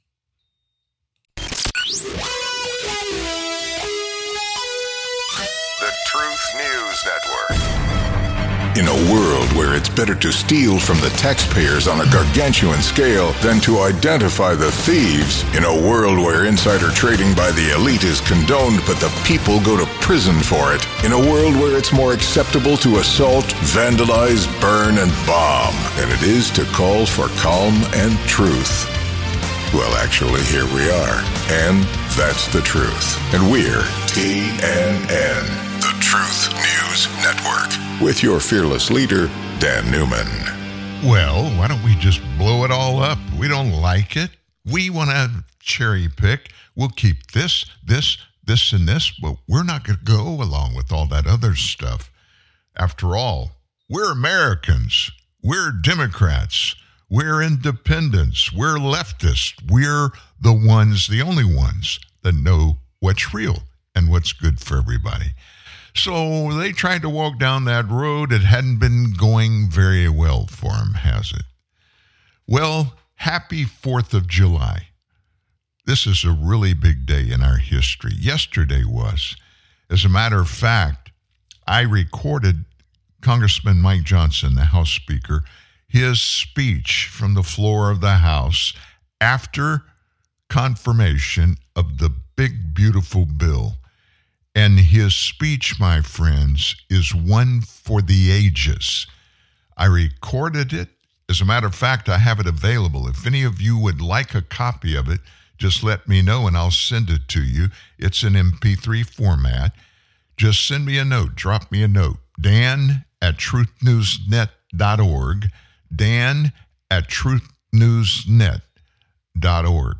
(You'll hear Hillary spell that out in her own words).